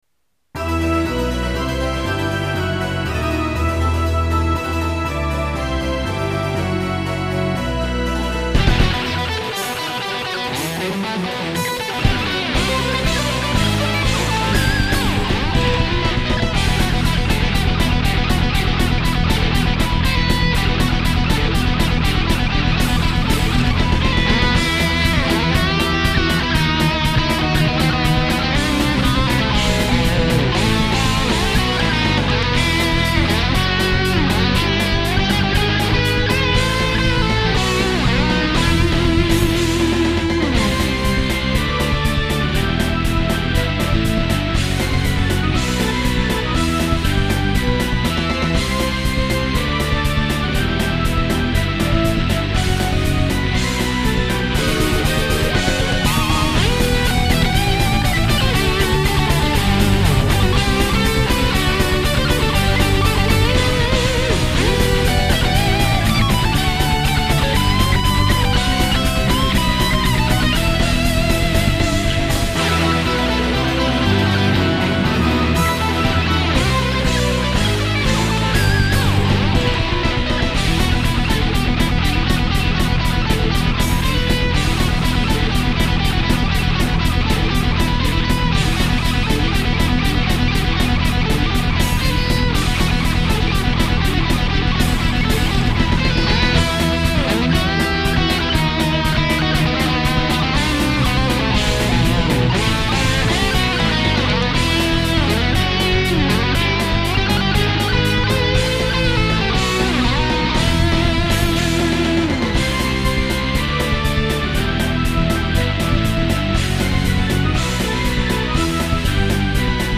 guitar instrumental
このページの曲はすべてコピー（またはｶｳﾞｧｰ）です。